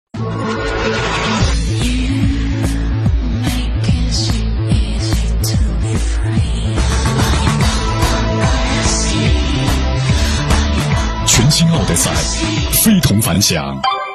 标签： 大气
配音风格： 浑厚 讲述 时尚 稳重 温情 大气 激情